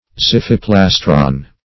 Search Result for " xiphiplastron" : The Collaborative International Dictionary of English v.0.48: Xiphiplastron \Xiph"i*plas"tron\, n.; pl.
xiphiplastron.mp3